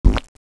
1 channel
SQUIRTNG.WAV